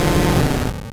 Cri d'Abo dans Pokémon Or et Argent.